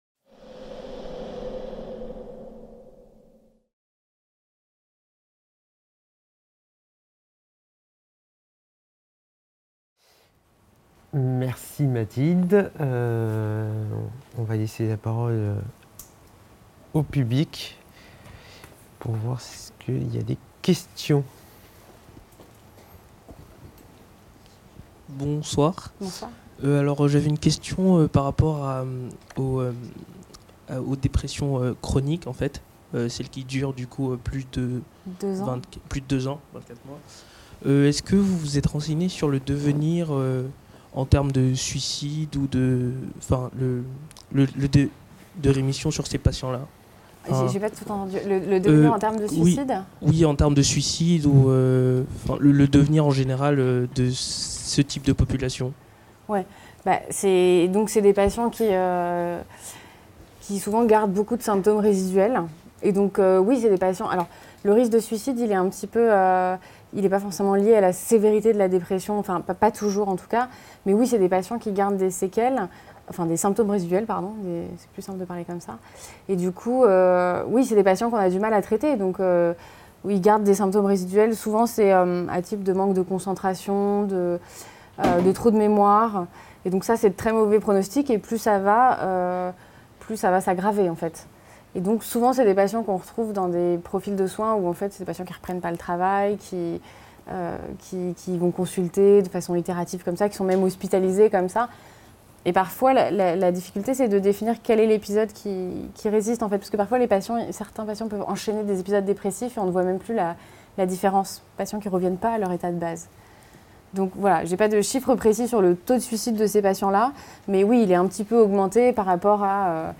2019 Thèse en poche | 02 B Dépression résistante : recommandations actuelles et pratiques professionnelles - table ronde | Canal U